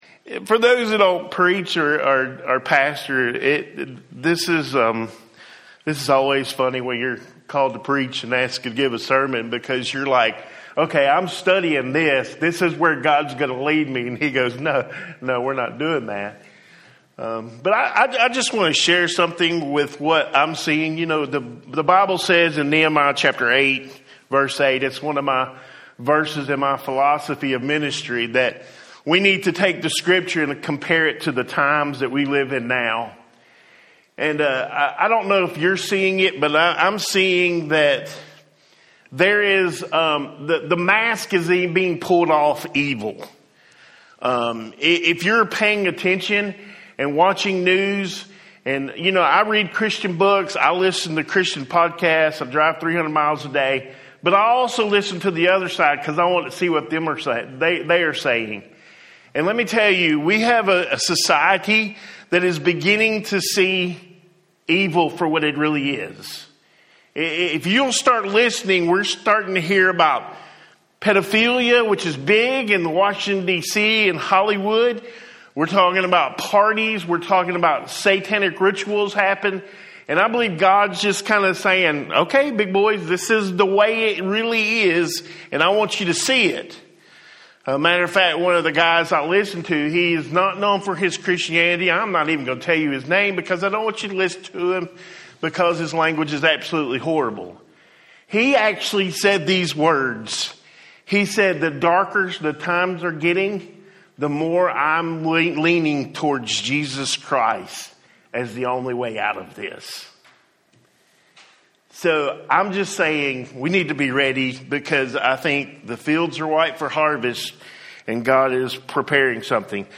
Home › Sermons › Blind But Now I See